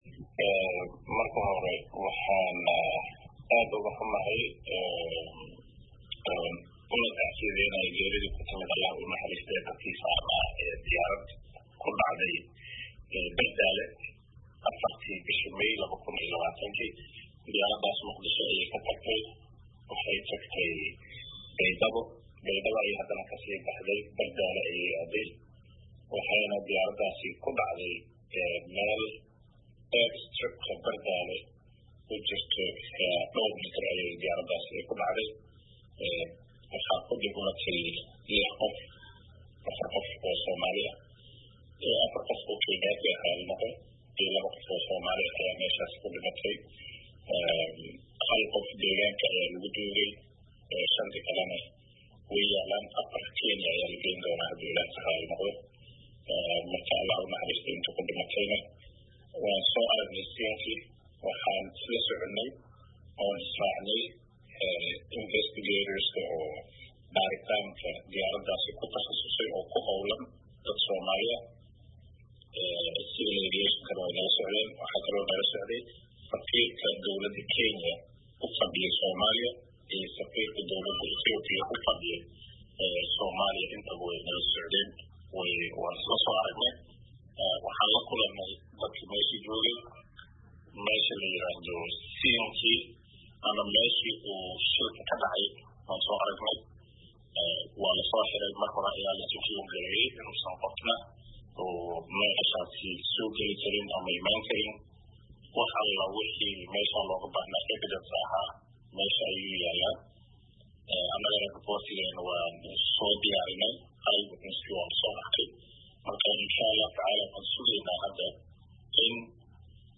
Wareysi: Wasiir Oomaar oo bixiyey xogta diyaaraddii ku dhacday garoonka Bardaale
Wasiirka Gaadiidka iyo Duulista Hawada Maxamed Cabdullaahi Salaad Oomaar oo la hadlay VOA ayaa sheegay inay gaareen halkii ay ku soo dhacday diyaaradu iyaga oo wata quburo ku xeel dheer baaritaanka shilalka nuucaan ah.